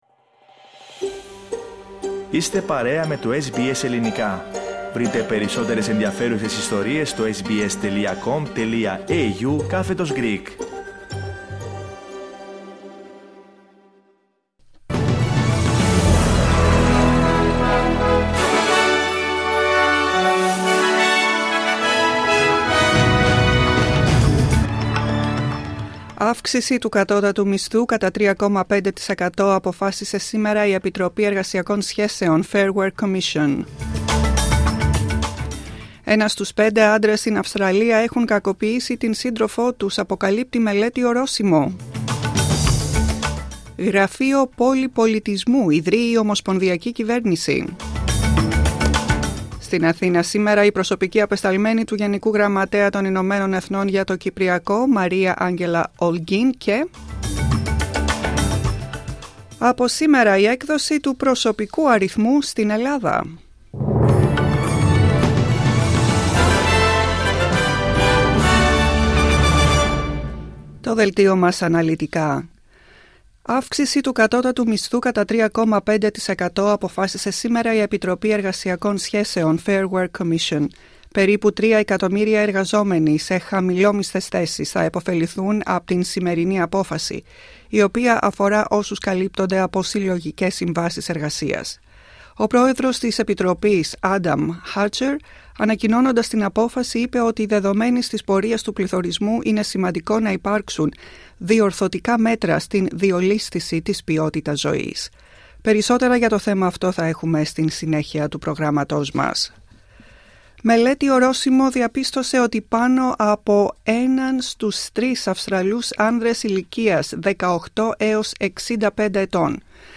Δελτίο ειδήσεων Τρίτη 03.06.2025